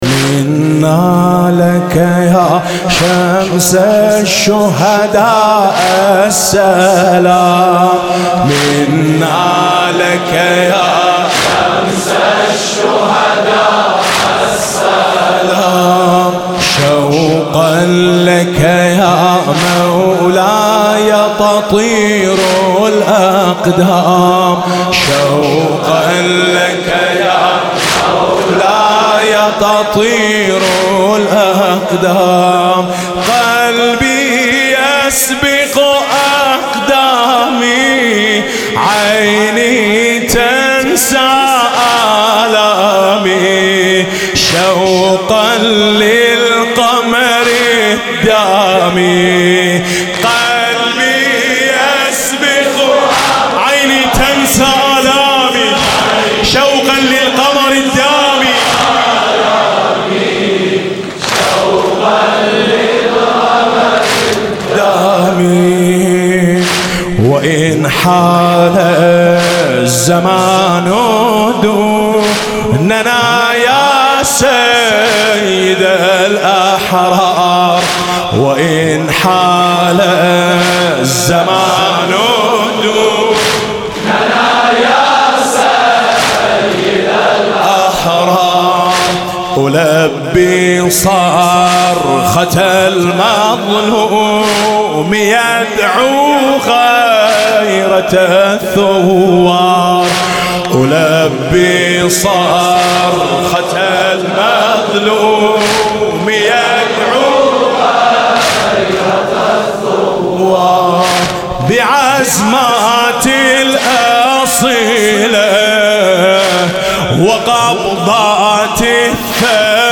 لطميات-الكوثر